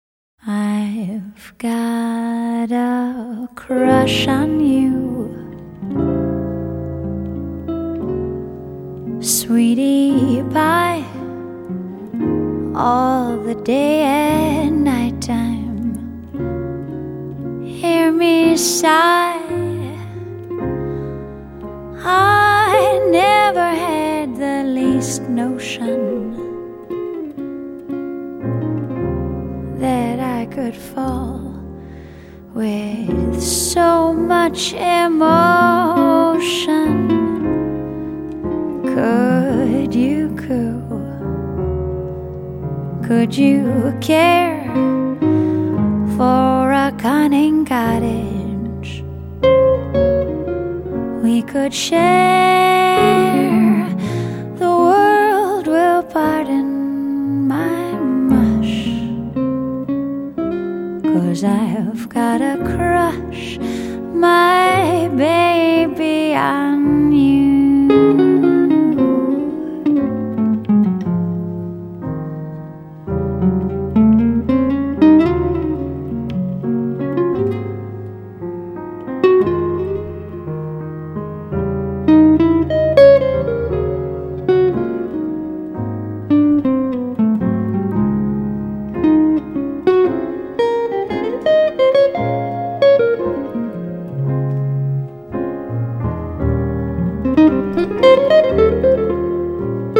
vocals
tenor saxophone, clarinet, flute
guitar
piano
bass
drums